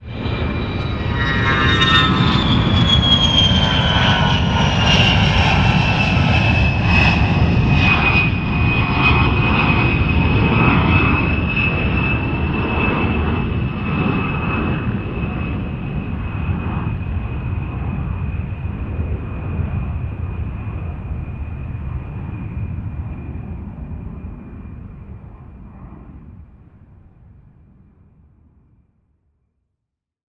1. Пассажирский самолёт пролетел (три варианта) n2. Пассажирский самолёт пролетел (три версии) n3. Пассажирский самолет пролетел (три варианта